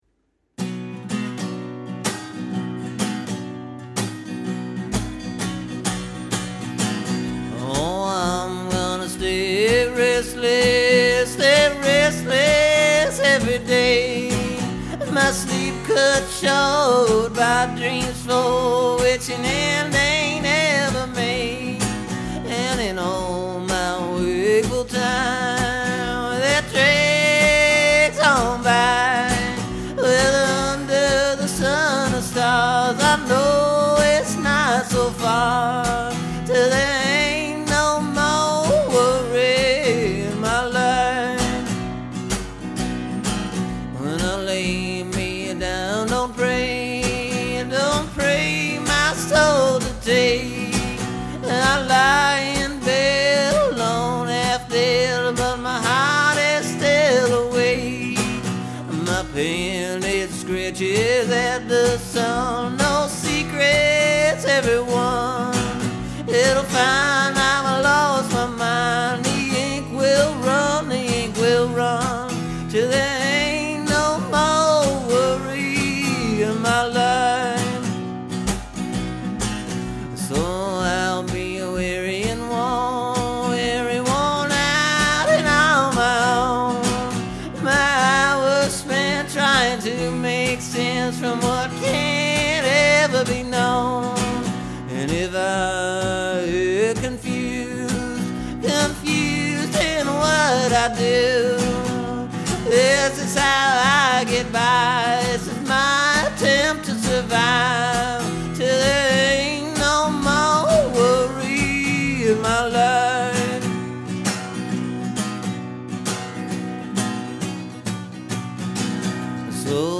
Just wasn’t working out slowly.
I like the new arrangement, though.